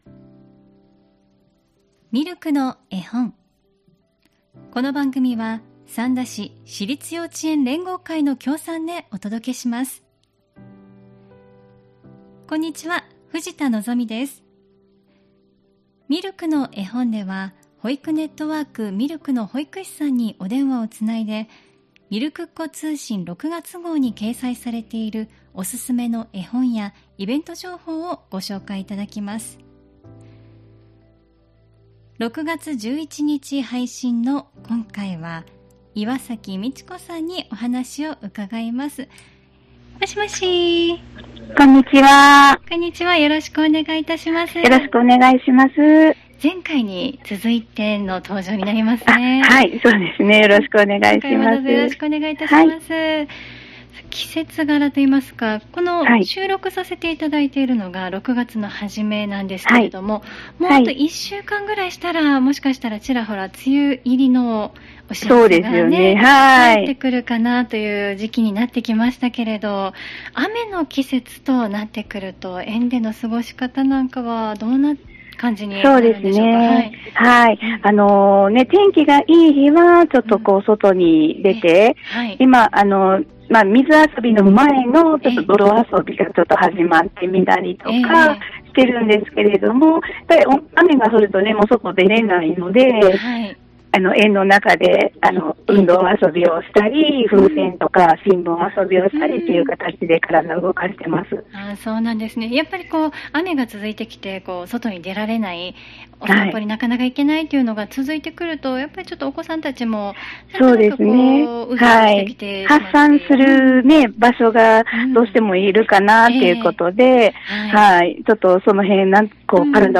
保育ネットワーク・ミルクの保育士さんにお電話をつないで、みるくっ子通信に掲載されているおすすめの絵本やイベント・施設情報などお聞きします。